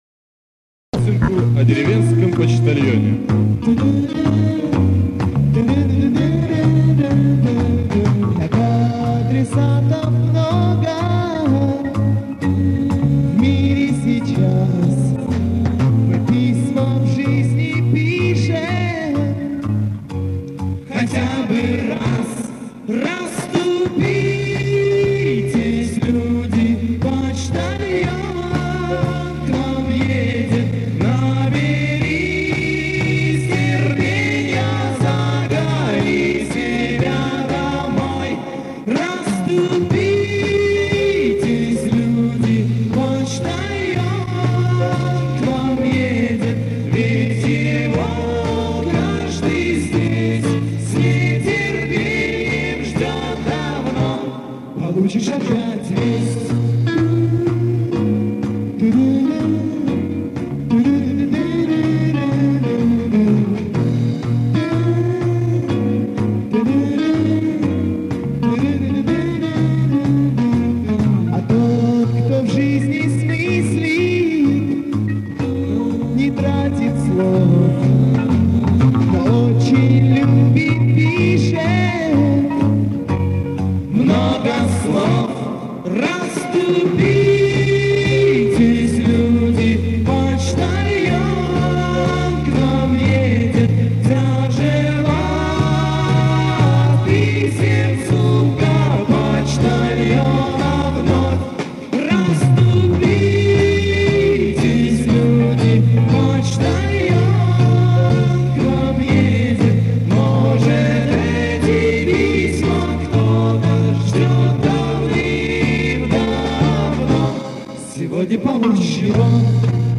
но перебор с низами